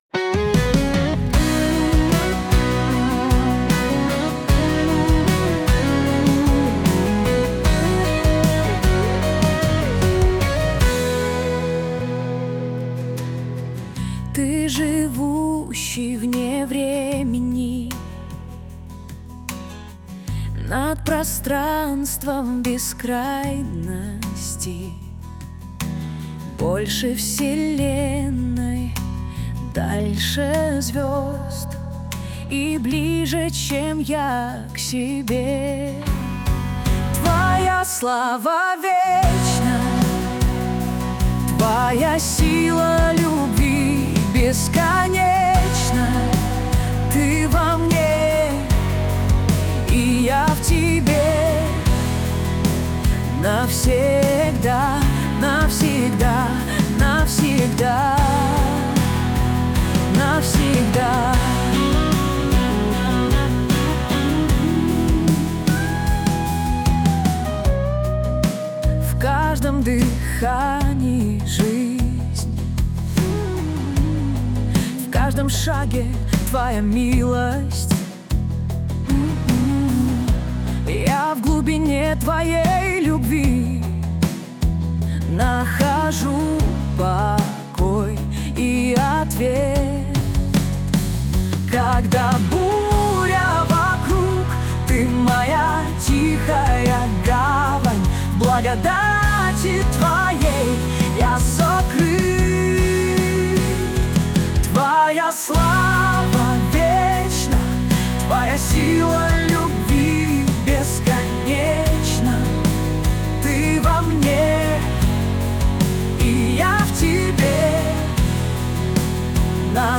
песня ai
117 просмотров 163 прослушивания 10 скачиваний BPM: 76